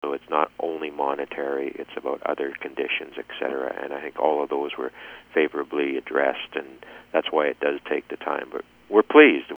Mayor Ron Toyota says bargaining took place in fits and starts over the last eight months or so.